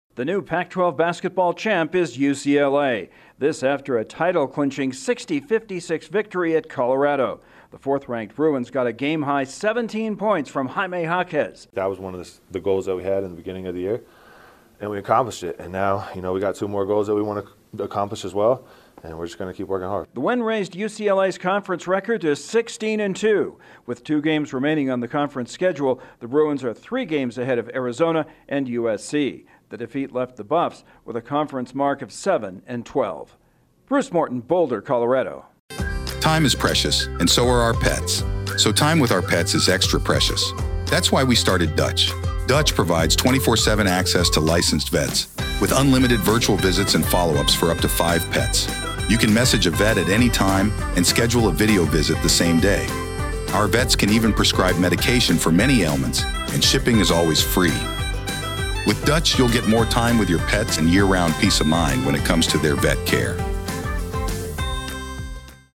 UCLA is one-third of the way toward its goal after a close win over Colorado. Correspondent